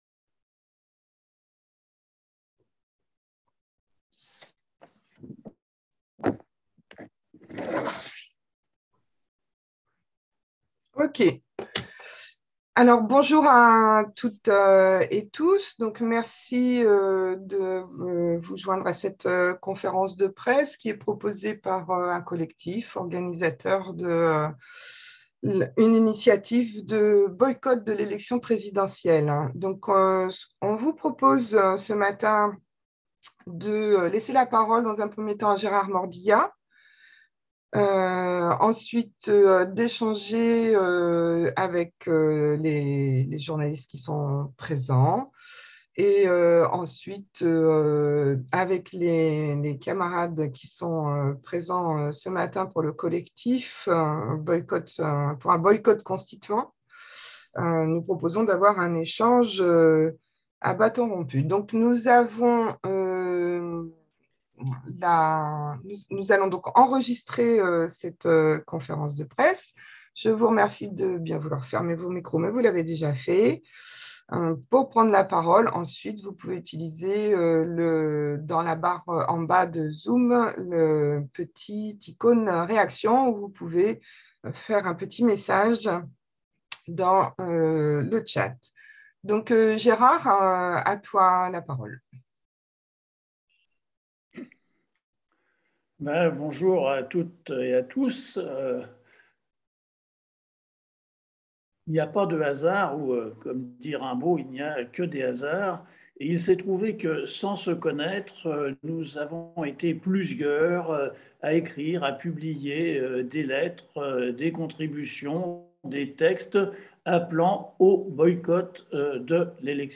Ecoutez ici l’enregistrement audio de la conférence de presse en ligne réalisée par les initiateurs de l’appel BOYCOTTONS L’ÉLECTION PRÉSIDENTIELLE !.